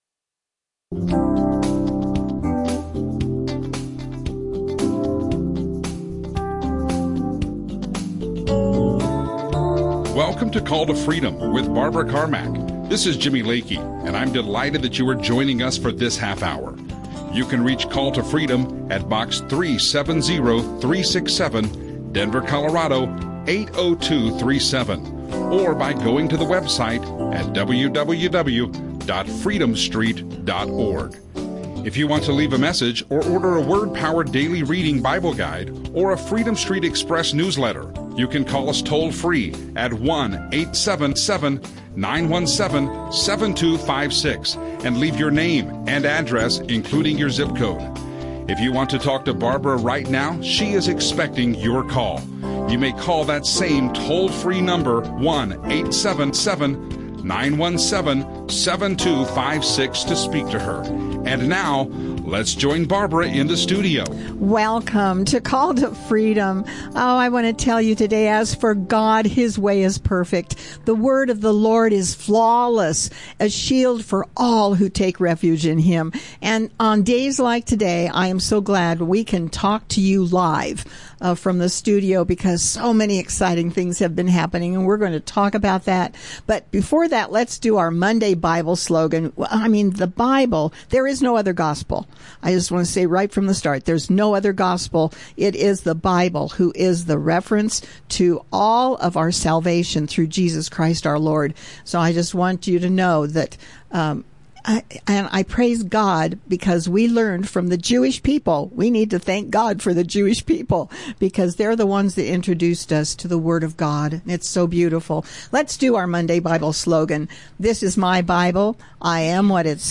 Trump radio show